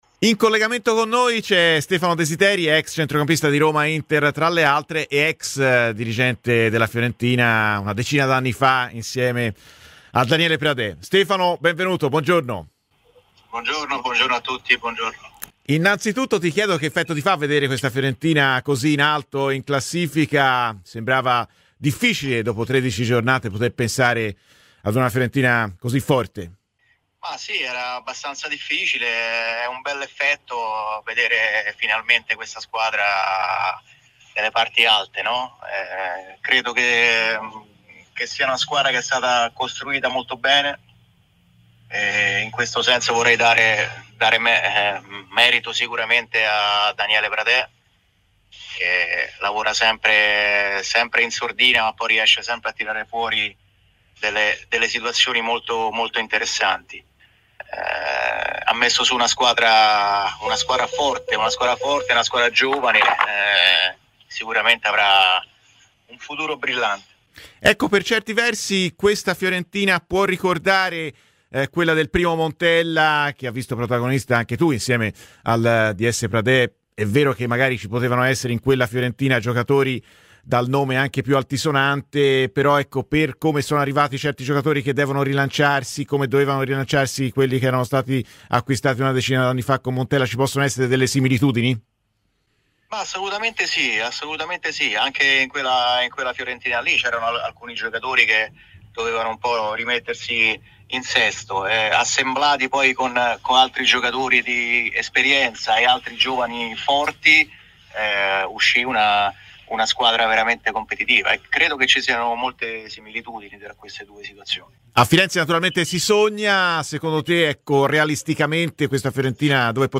Stefano Desideri, ex centrocampista nonché dirigente della Fiorentina al fianco di Daniele Pradè nel suo primo ciclo a Firenze, ha parlato a Radio FirenzeViola nel corso di "Viola Amore Mio".